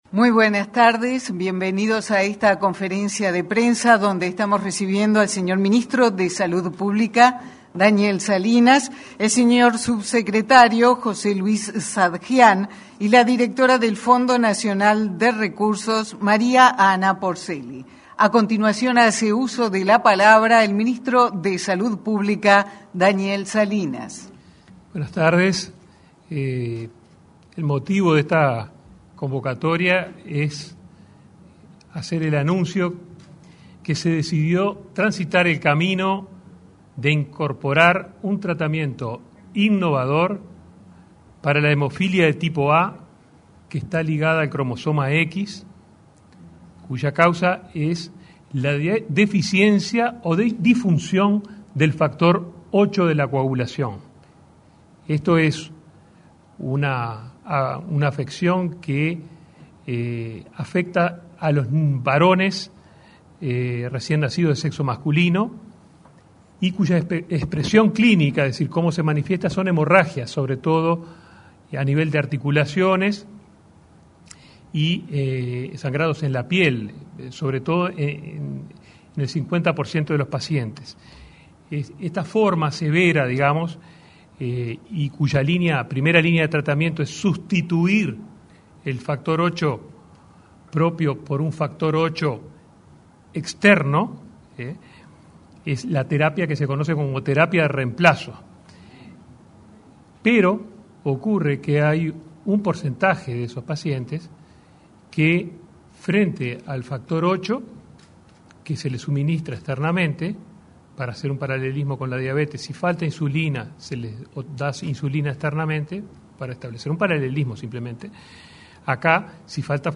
Conferencia de prensa de autoridades del Ministerio de Salud Pública
Conferencia de prensa de autoridades del Ministerio de Salud Pública 15/11/2021 Compartir Facebook X Copiar enlace WhatsApp LinkedIn Este lunes 15, el ministro de Salud Pública, Daniel Salinas, acompañado por el subsecretario de la cartera, José Luis Satdjian, y la directora del Fondo Nacional de Recursos, María Ana Porcelli, brindó una conferencia de prensa en la Torre Ejecutiva.